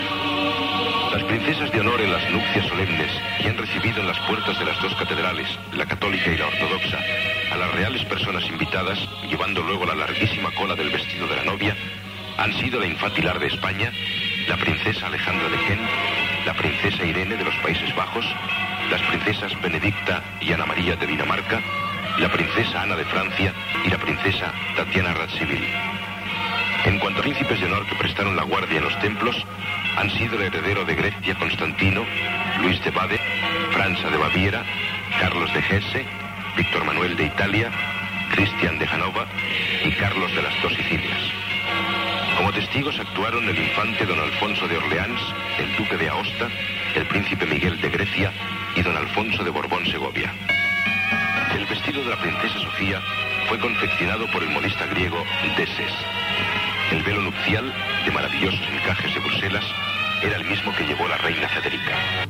Crònica del casament dels prínceps Juan Carlos de Borbón i Sofia de Grècia i Dinamarca a Atenes el maig de 1962
Informatiu